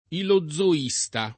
vai all'elenco alfabetico delle voci ingrandisci il carattere 100% rimpicciolisci il carattere stampa invia tramite posta elettronica codividi su Facebook ilozoista [ ilo zz o &S ta ] s. m. e f. (filos.); pl. m. ‑sti